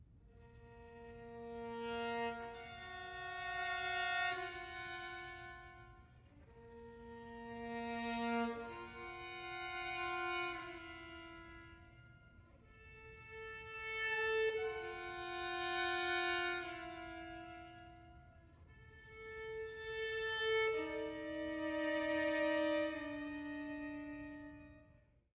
includes two works for unaccompanied violin
It can at times be almost unbearably intense.